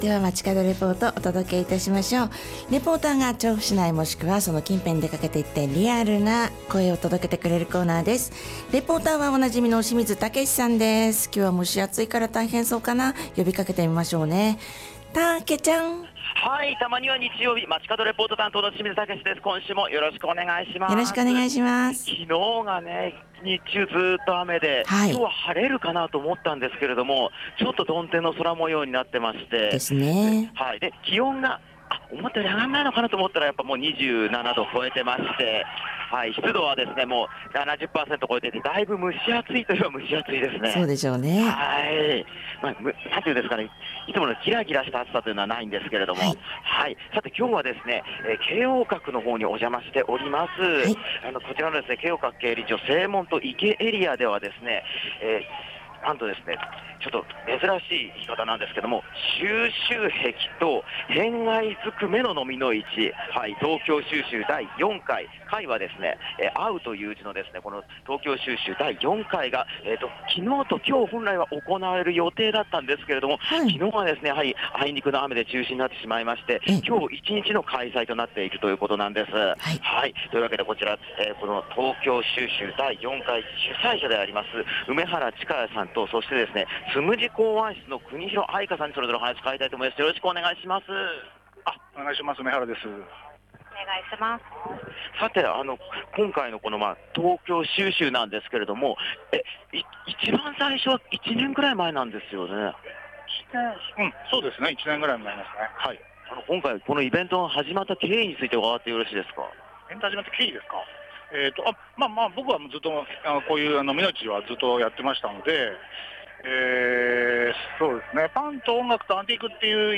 真夏日の予想から一転した曇天の空の下からお届けした本日の街角レポートは、
京王閣にお邪魔をして 本日開催中の「東京蒐集第4会」の会場からのレポートです！